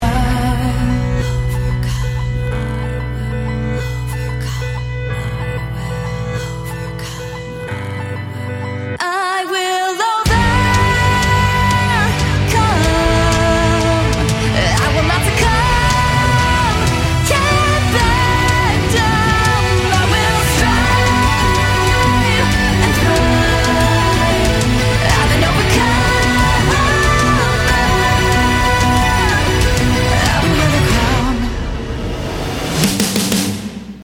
Raw, powerful, and unrelenting